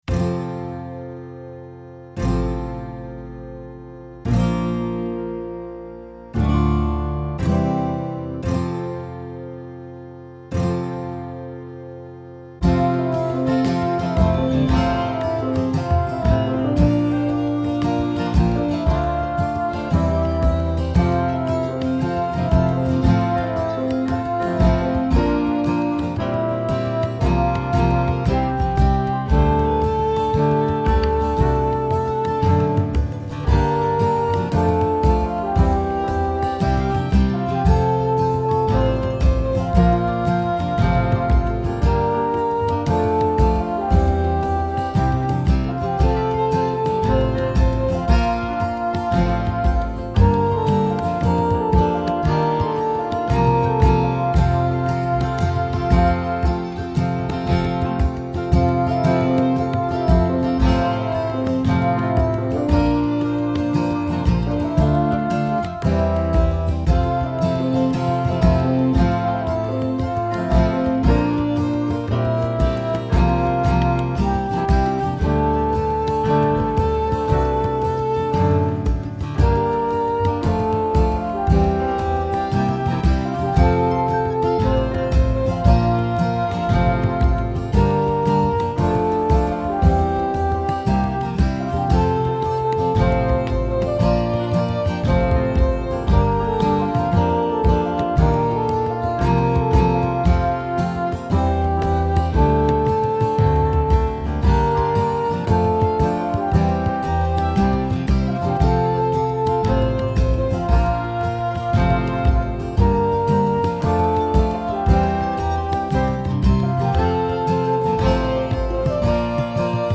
My backing goes a bit Irish: